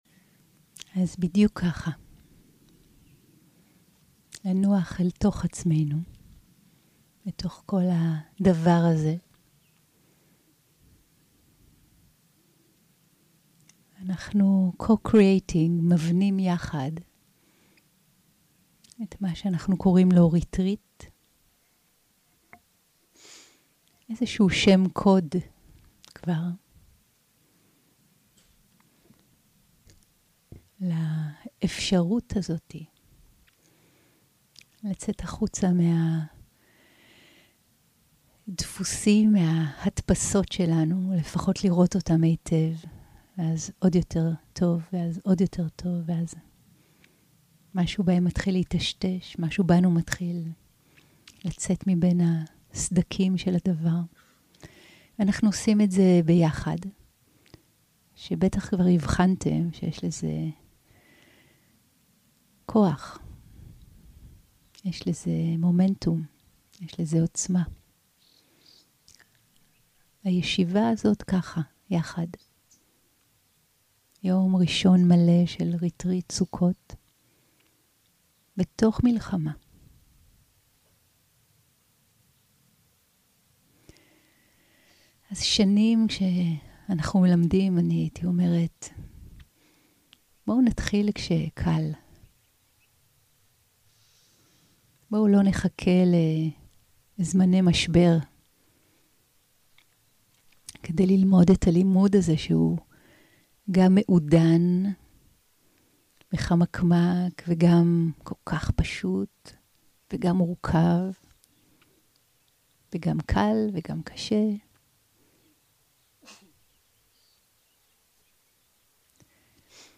יום 2 - הקלטה 2 - בוקר - הנחיות למדיטציה - גישות פואטיות לתרגול + הליכה 0:00 0:00 סוג ההקלטה: סוג ההקלטה: שיחת הנחיות למדיטציה שפת ההקלטה: שפת ההקלטה: עברית